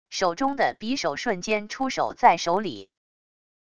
手中的匕首瞬间出手在手里wav音频